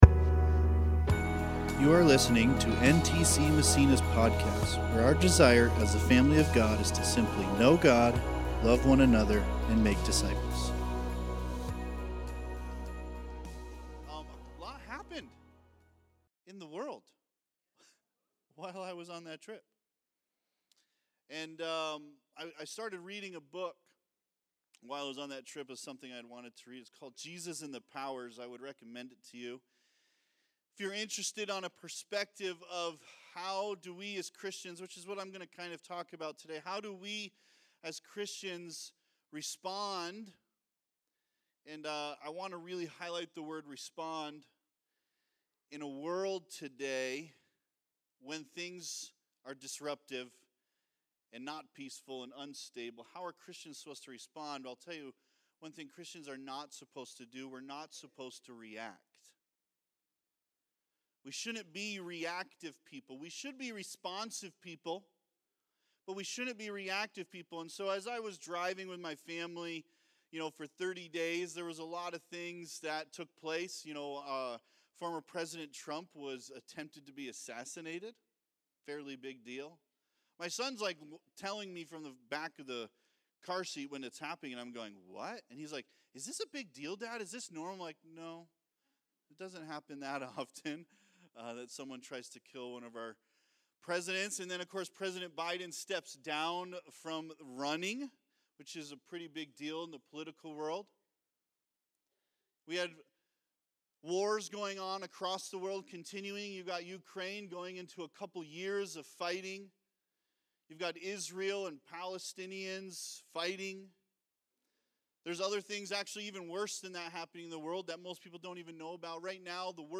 2024 Citizens of Heaven Preacher